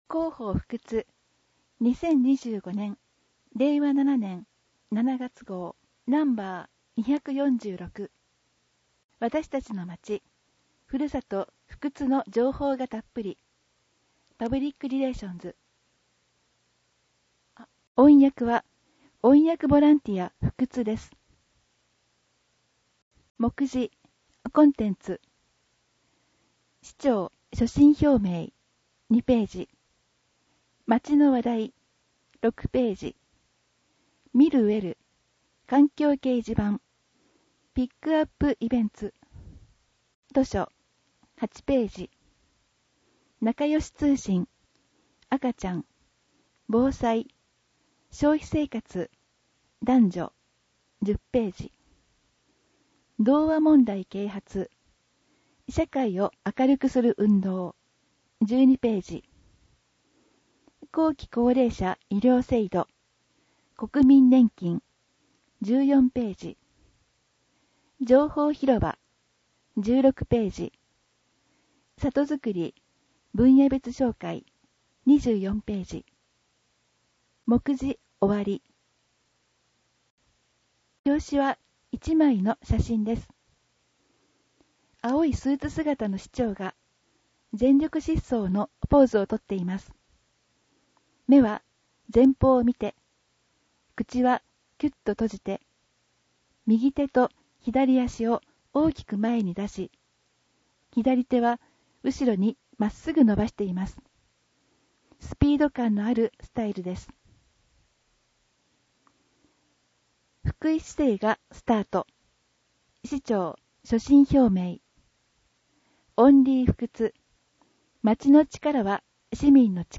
広報ふくつを音声で聞けます
音訳ボランティアふくつの皆さんが、毎号、広報ふくつを音訳してくれています。